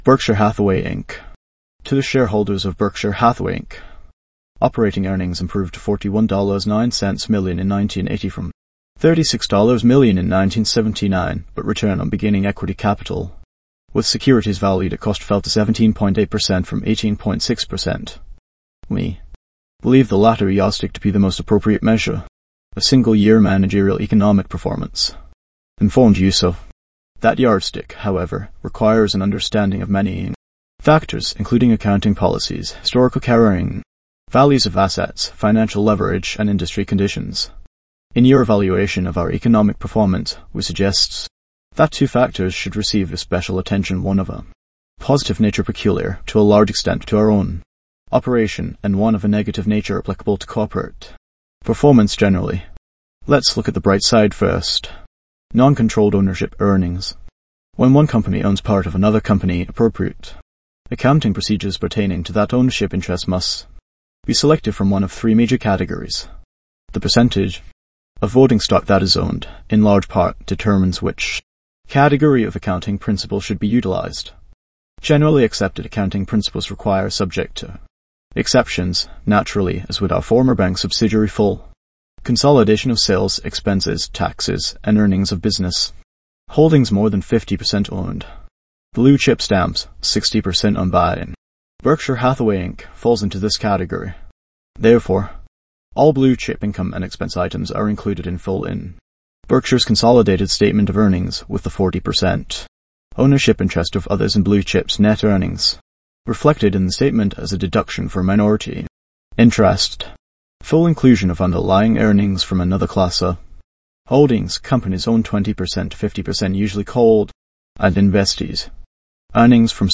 value-investors-tts